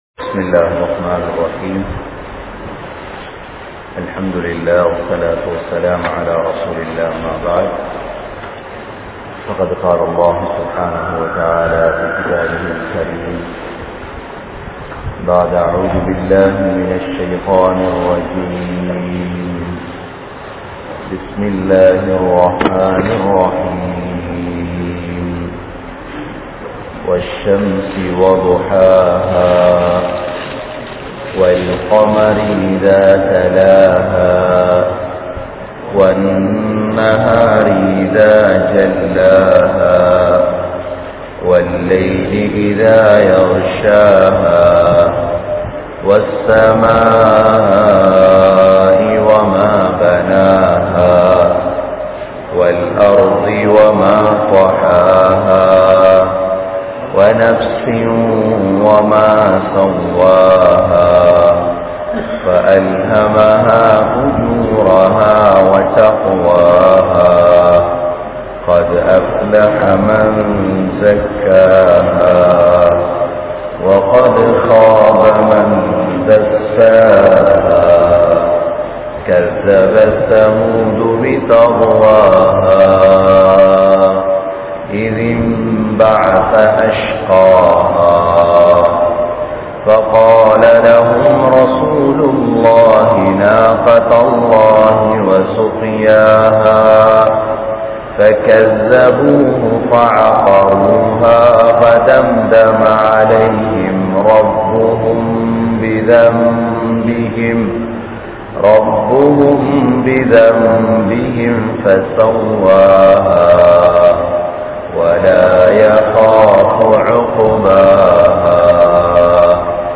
Al Quran Koorum 20 Vahaiyaana Ullangal (அல்குர்ஆன் கூறும் 20 வகையான உள்ளங்கள்) | Audio Bayans | All Ceylon Muslim Youth Community | Addalaichenai
Aluthgama, Dharga Town, Meera Masjith(Therupalli)